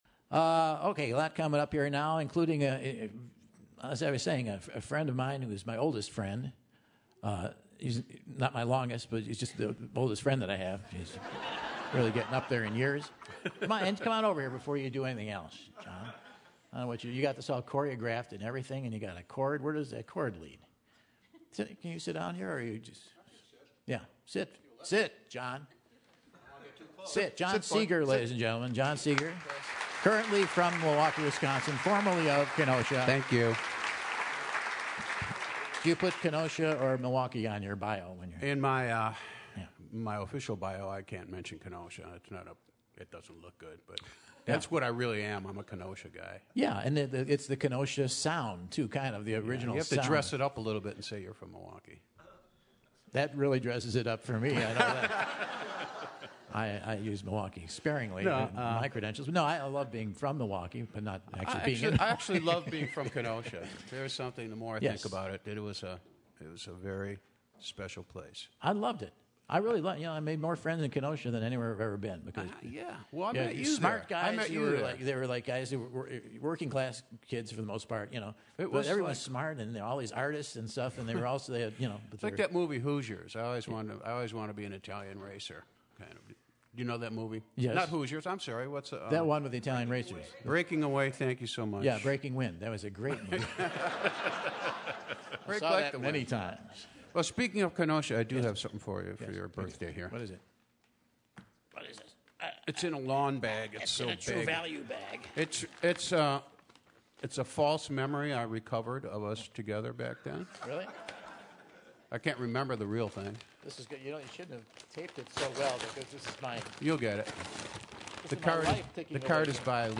takes to the Terrace stage for the 30th Anniversary show!